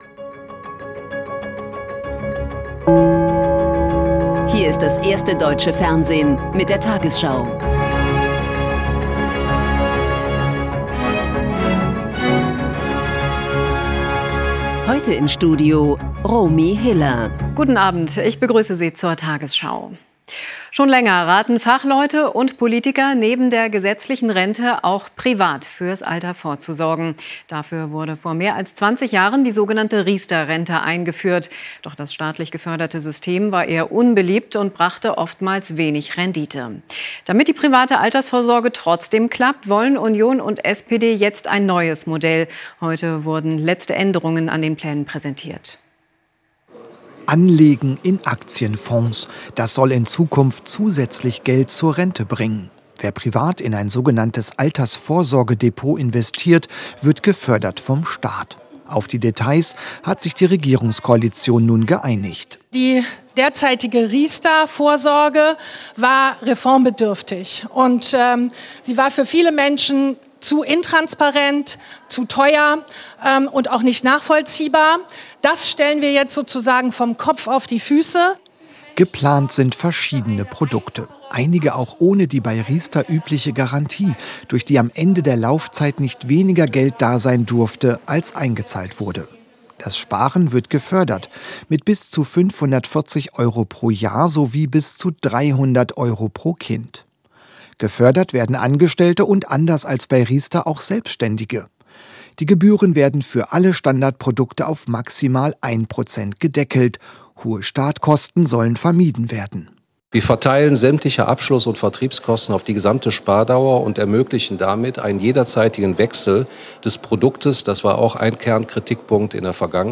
tagesschau 20:00 Uhr, 24.03.2026 ~ tagesschau: Die 20 Uhr Nachrichten (Audio) Podcast